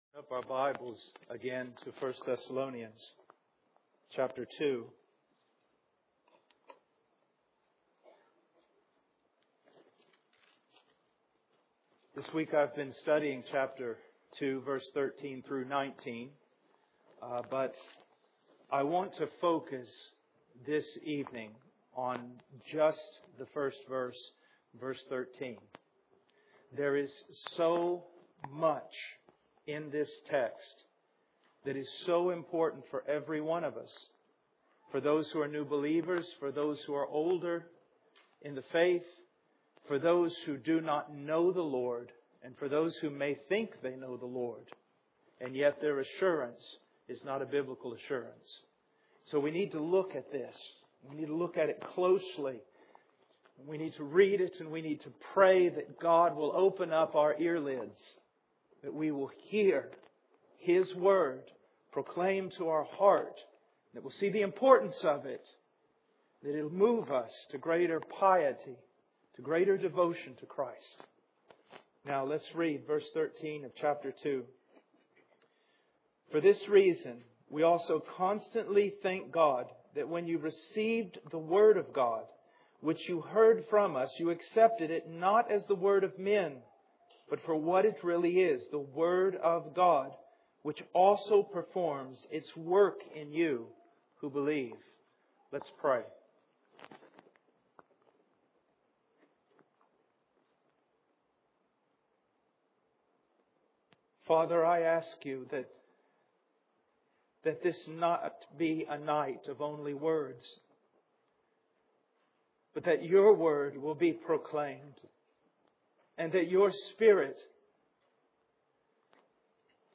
In this sermon, the preacher emphasizes the power and importance of the word of God. He quotes Hebrews 4:12, which describes the word of God as living, active, and able to discern the thoughts and intentions of the heart.